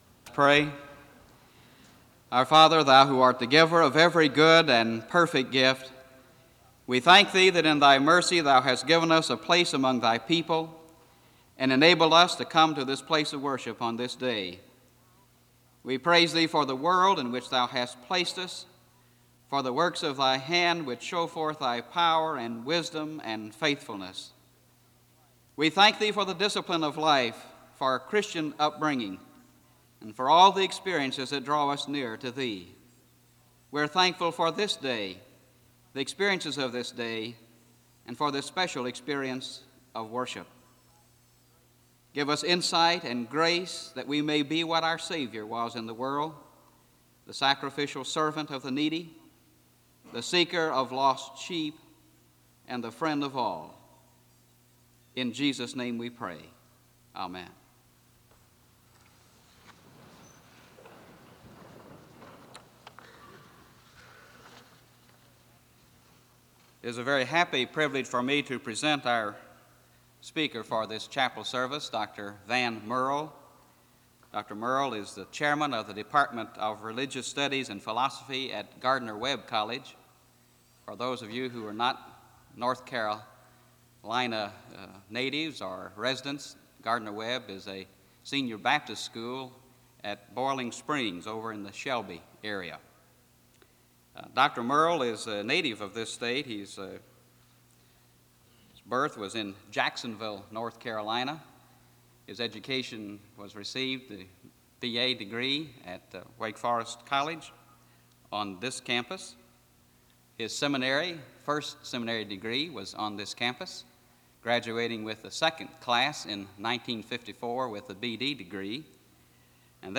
Location Wake Forest (N.C.)
SEBTS Chapel and Special Event Recordings